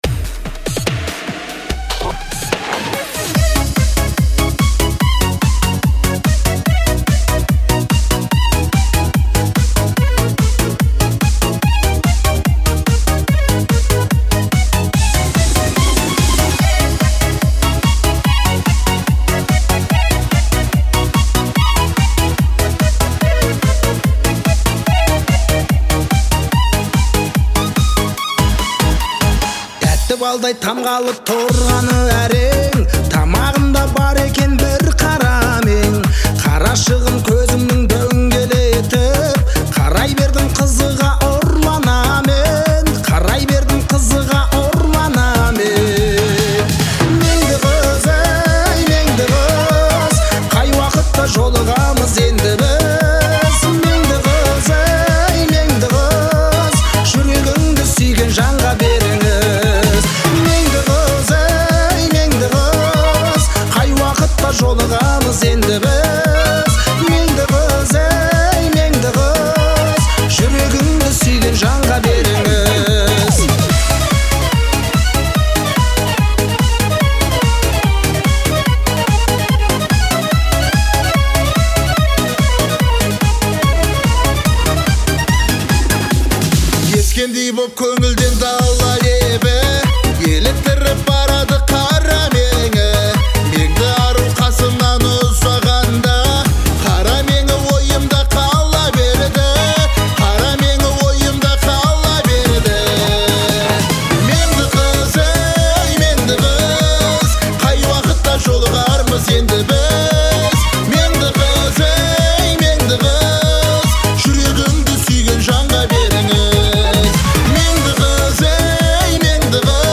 это яркая и запоминающаяся песня в жанре поп